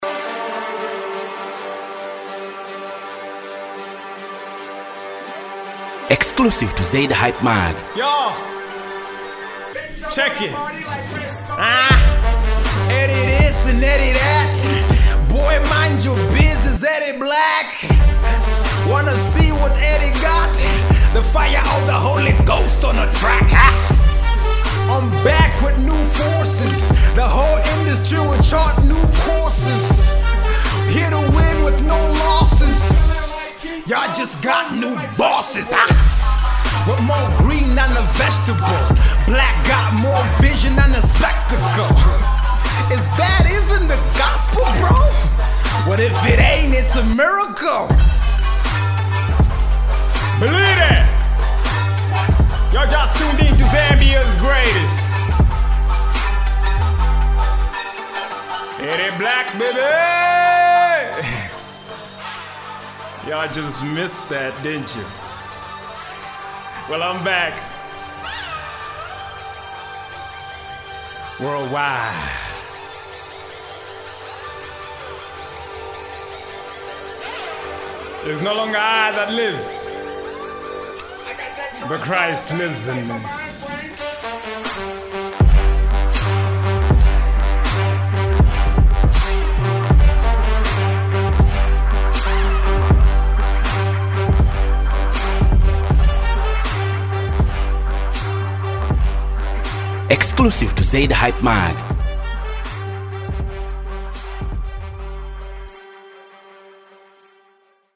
Christian Recording HipHop
The rapper spits his finest bars fused with Gospel lyrics.